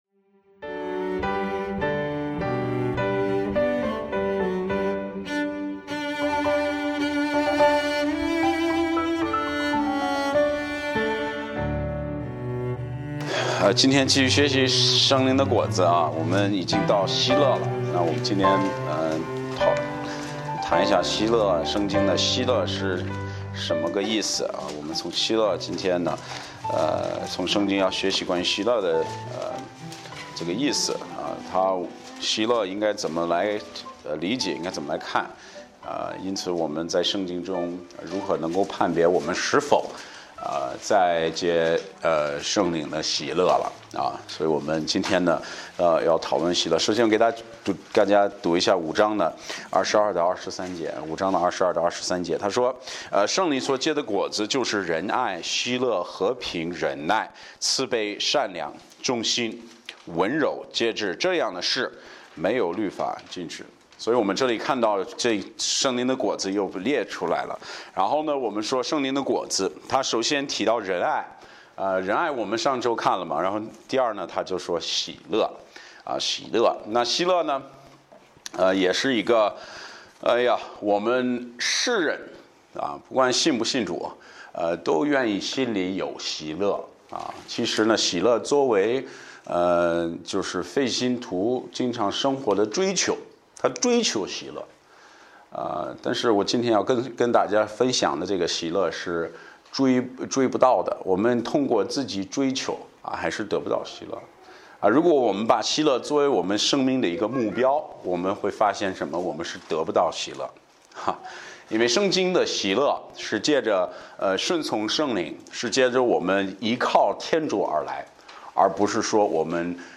Bible Text: 迦拉太书 5：22-23 | 讲道者